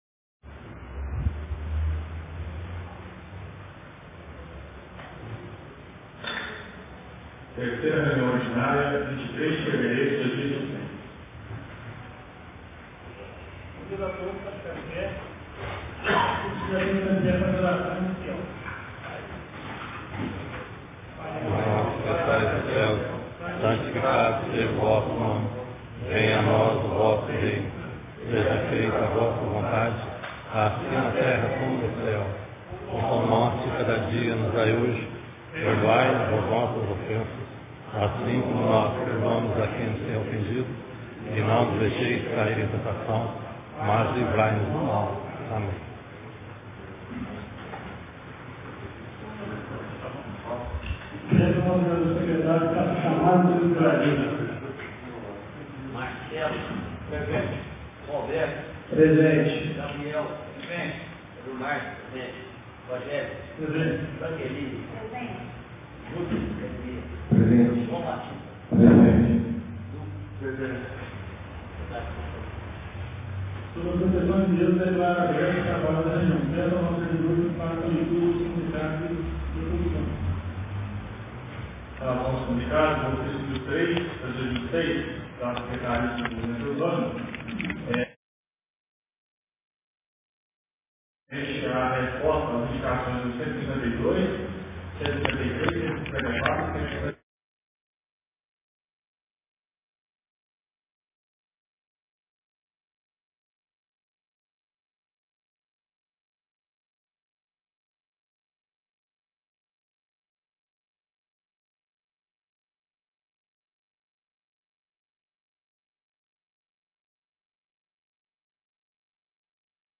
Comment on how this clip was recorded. Ata da 3ª Reunião Ordinária de 2026